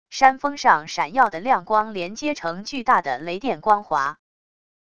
山峰上闪耀的亮光连接成巨大的雷电光华wav音频